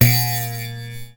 Ein Landesound
boing.wav